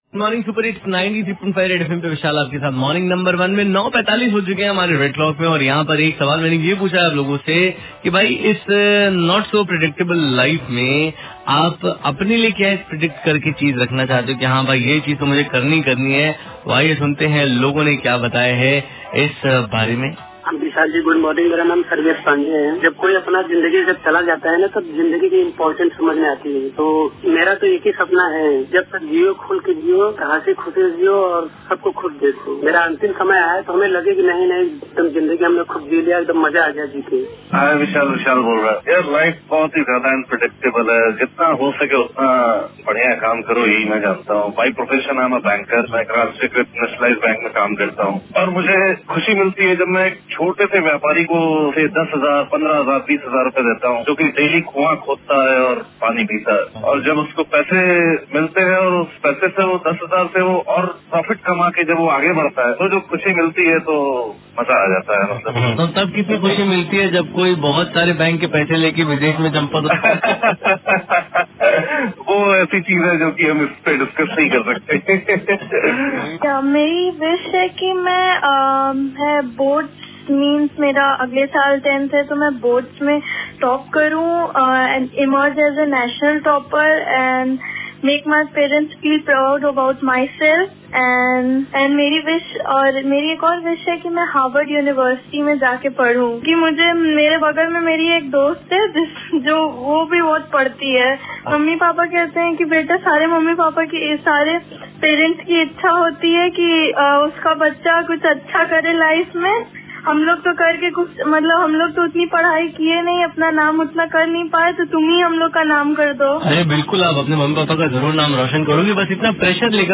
WITH CALLER